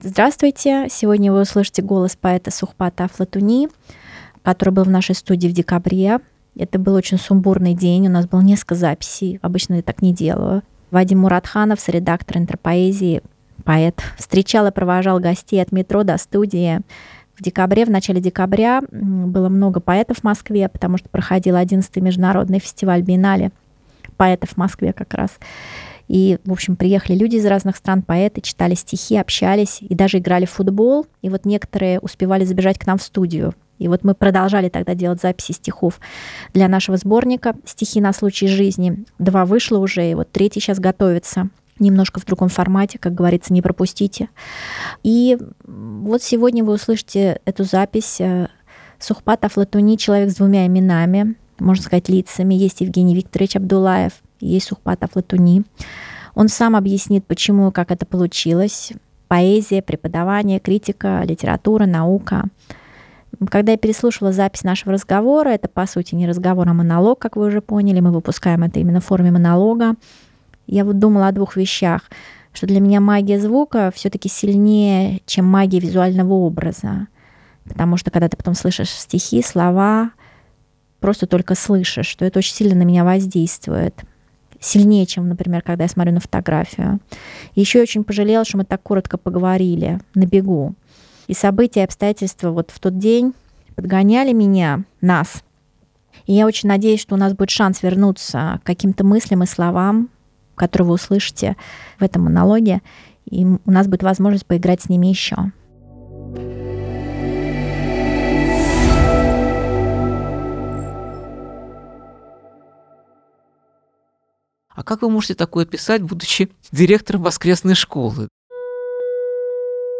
Третий выпуск поэтических монологов в рамках подкаста «Беседы».